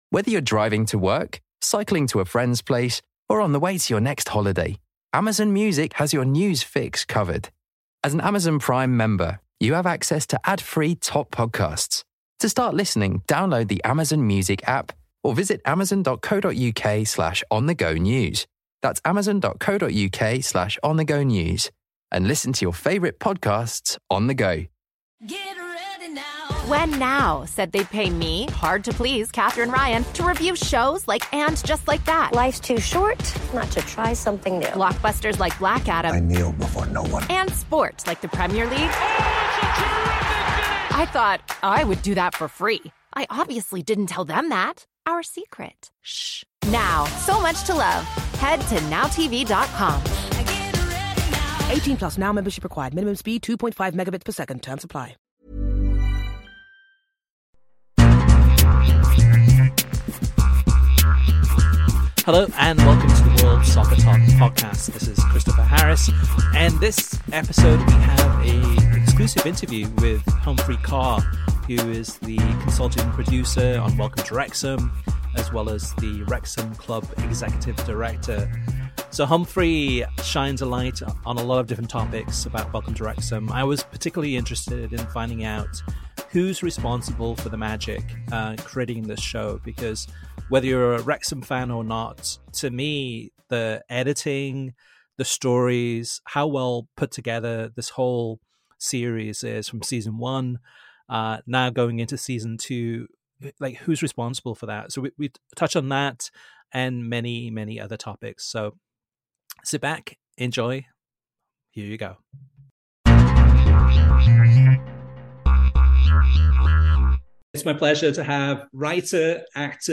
Welcome to Wrexham, season 2: Humphrey Ker interview